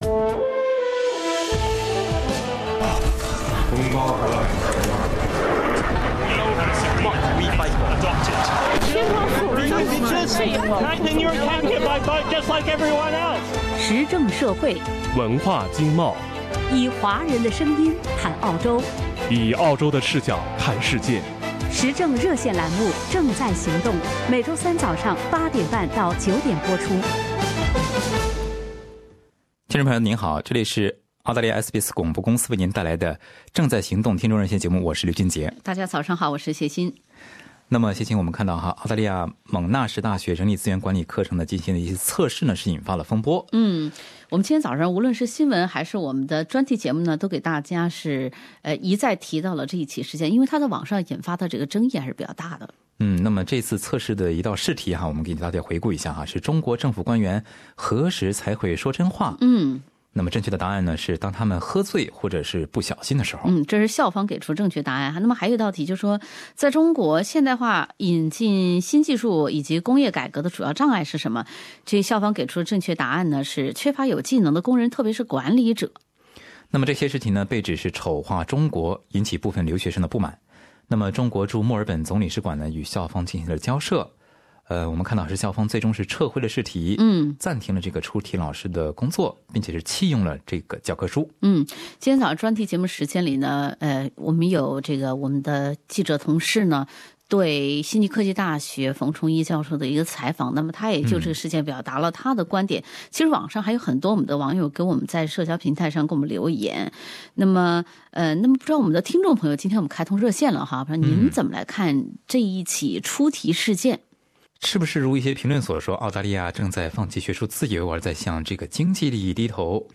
不少听众参与了 节目讨论。
以下为听众热线的部分内容：